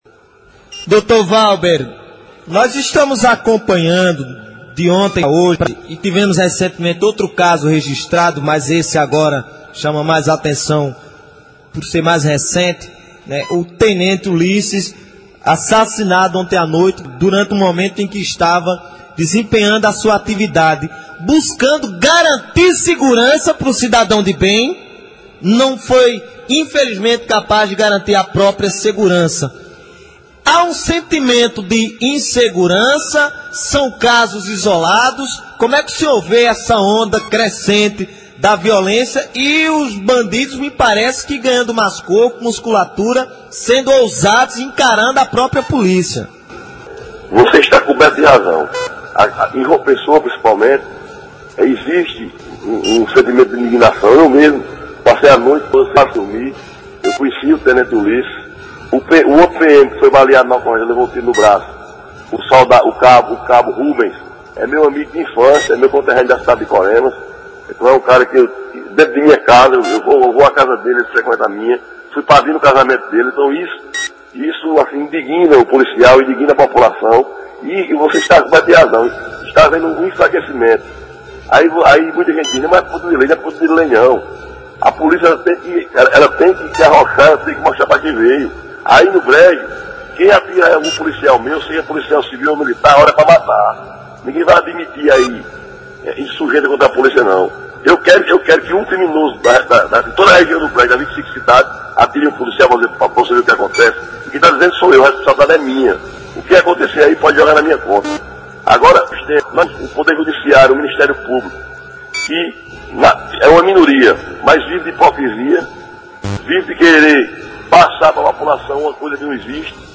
Áudio cedido pelo Jornal da Integração/Rádio Integração 680 AM – Bananeiras/PB